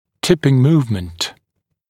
[‘tɪpɪŋ ‘muːvmənt][‘типин ‘му:вмэнт]наклонное перемещение, опрокидывающее движение